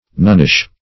Search Result for " nunnish" : The Collaborative International Dictionary of English v.0.48: Nunnish \Nun"nish\, a. Of, pertaining to, or resembling a nun; characteristic of a nun.